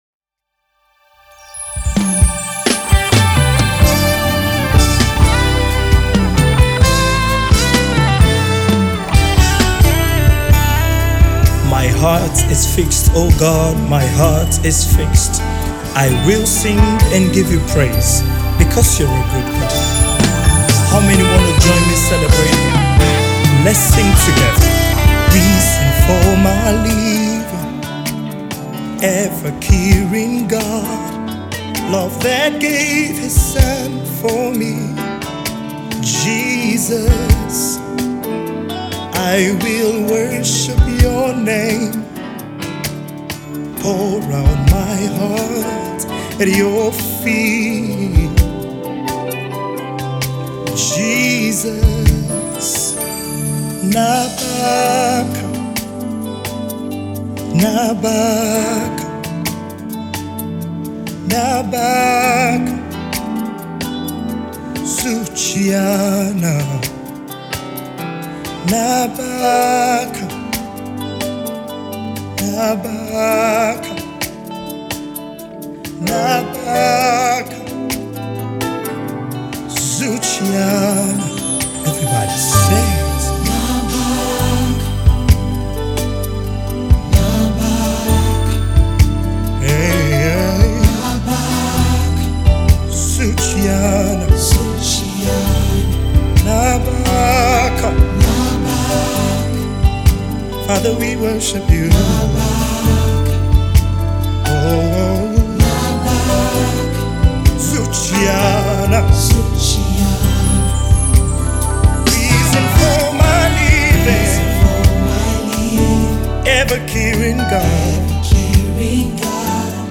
heartfelt worship
is a song of absolute surrender